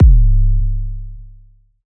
BWB VAULT 808 (Edm).wav